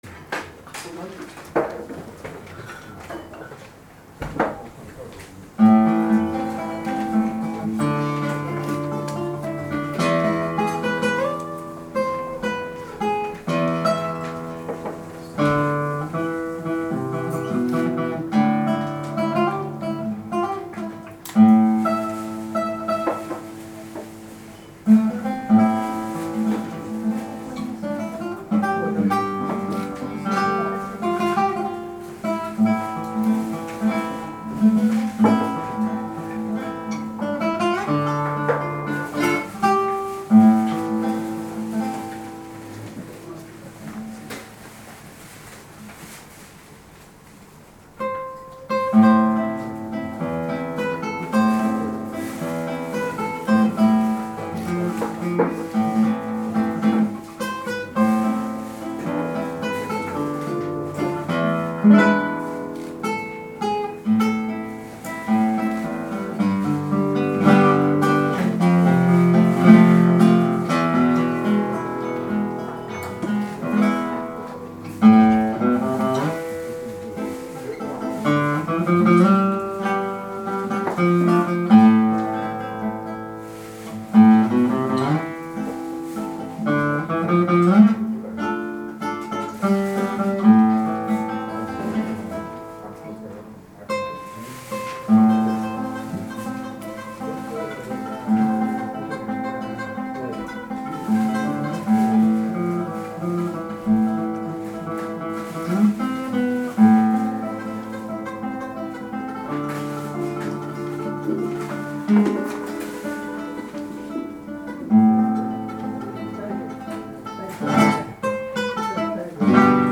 偕行社の文化祭に参加しました。